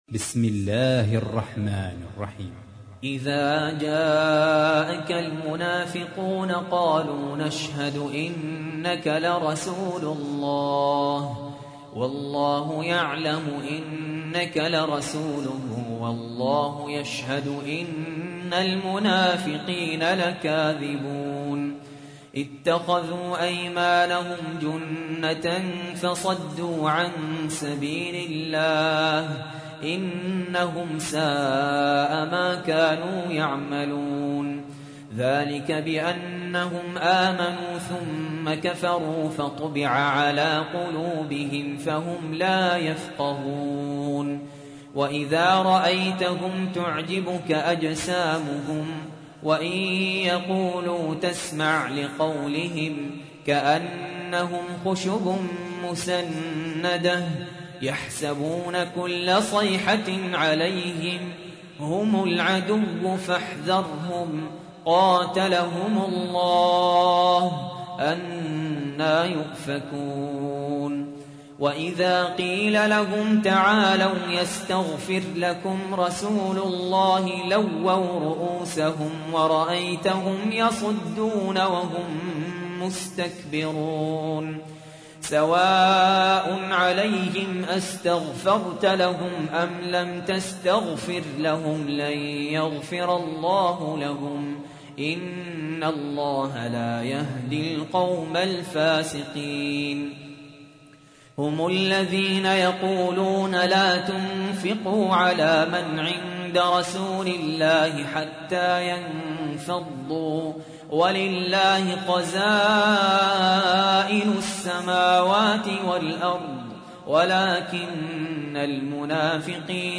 تحميل : 63. سورة المنافقون / القارئ سهل ياسين / القرآن الكريم / موقع يا حسين